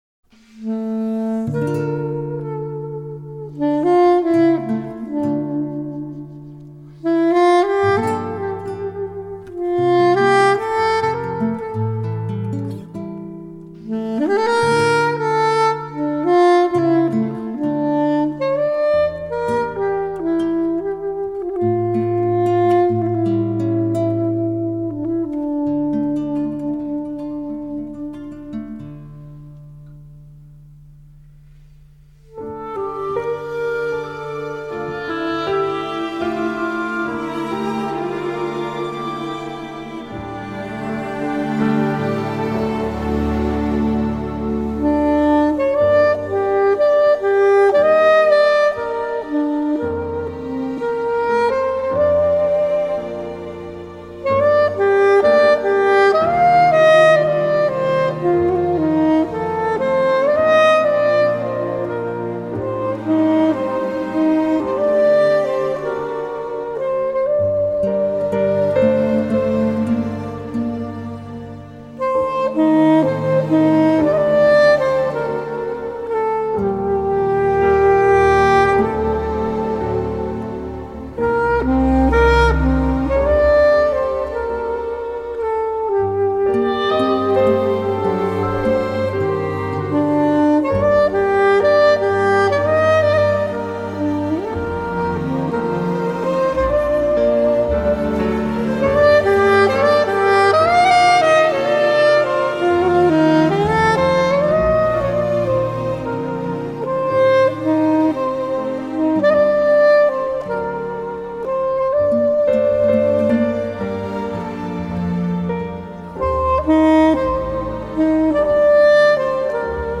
Genre: Jazz
Acoustic Guitar
Mixed at Capitol Studios, Los Angeles, CA.